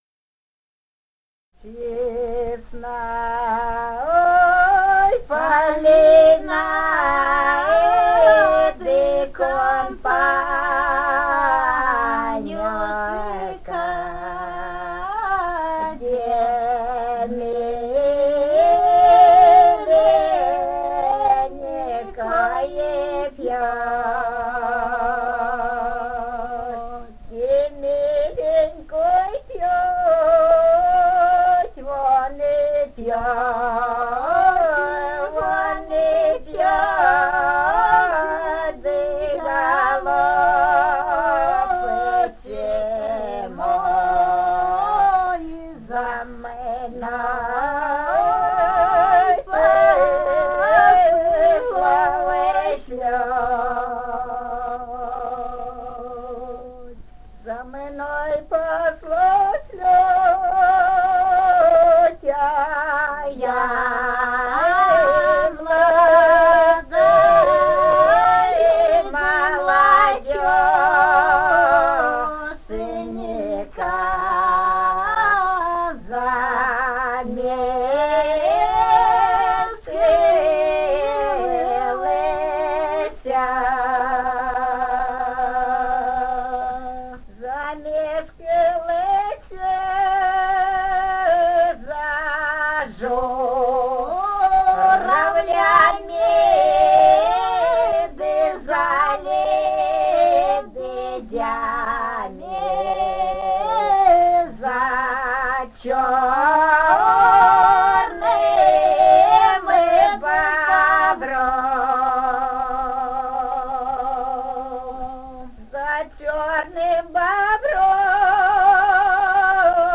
полевые материалы
Румыния, с. Переправа, 1967 г. И0973-10